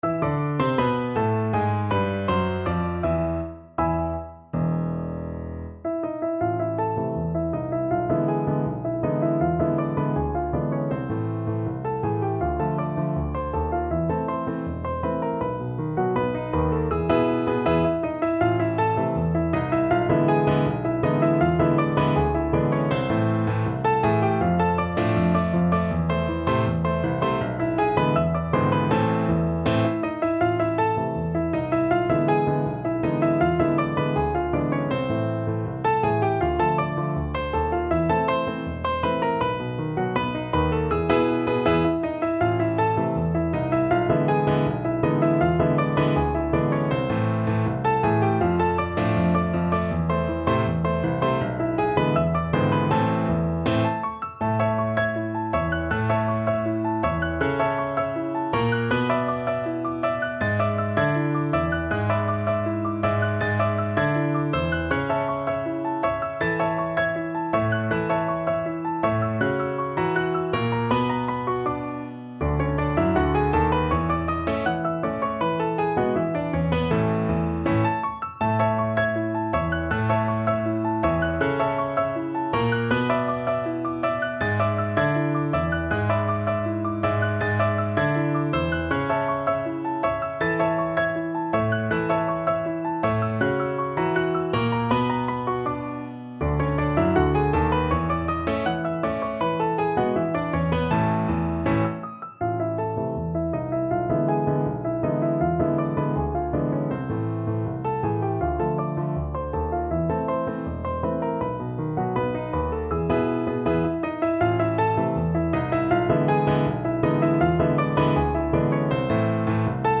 Free Sheet music for Piano Four Hands (Piano Duet)
Brazilian choro music piece
2/4 (View more 2/4 Music)
World (View more World Piano Duet Music)